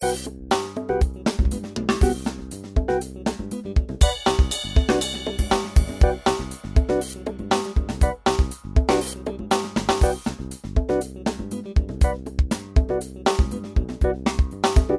backing tracks
Lydian Mode